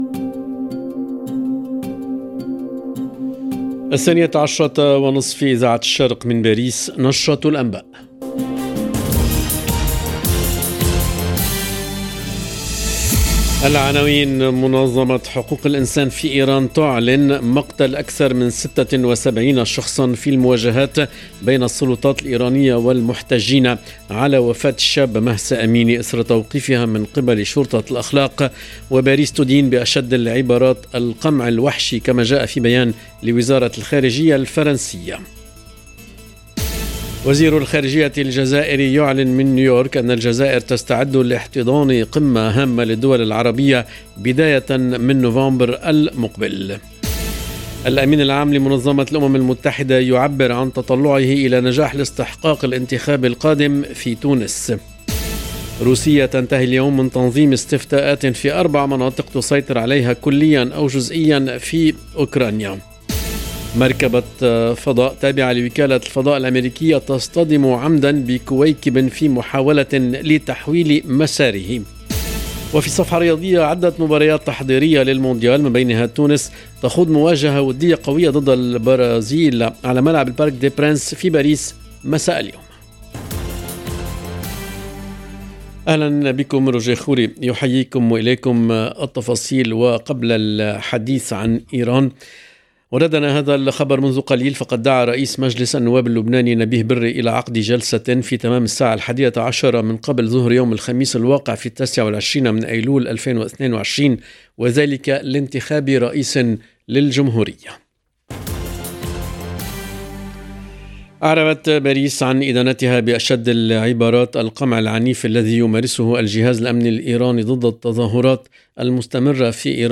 LE JOURNAL EN LANGUE ARABE DE MIDI 30 DU 27/09/22